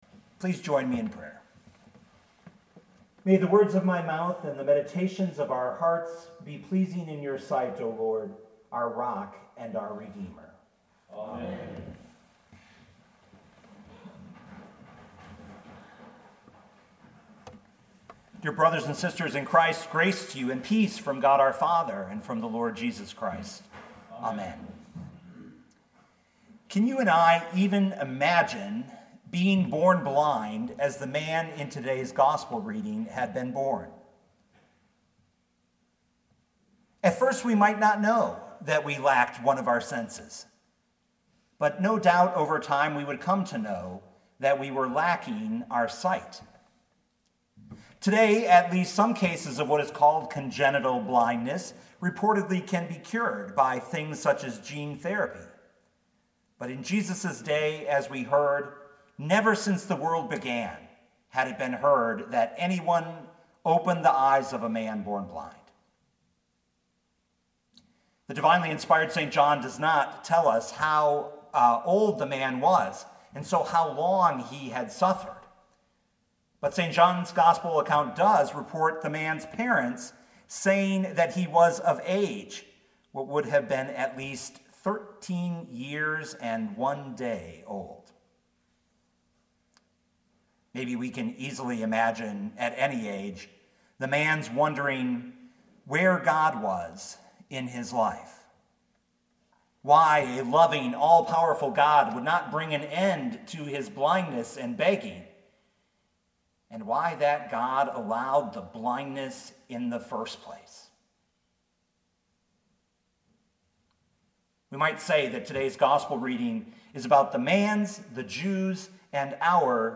2020 John 9:1-41 Listen to the sermon with the player below, or, download the audio.